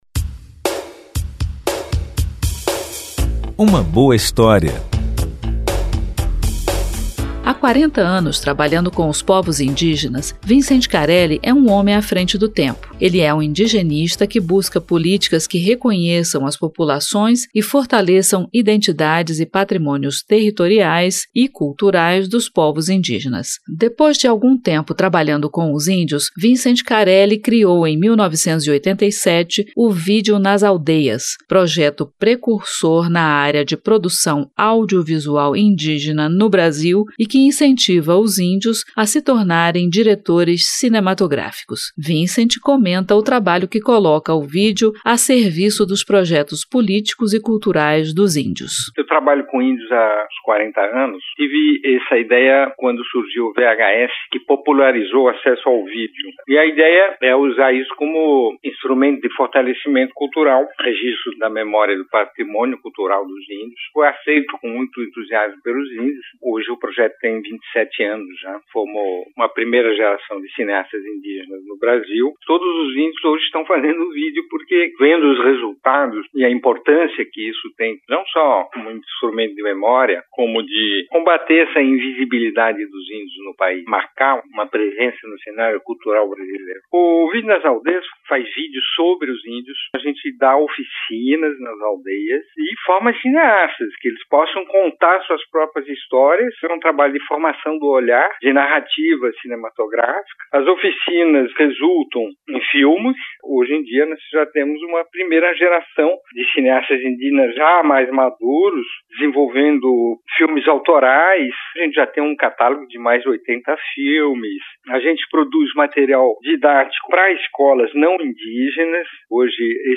Entrevista com o idealizador do Vídeo nas Aldeias e documentarista, Vincent Carelli.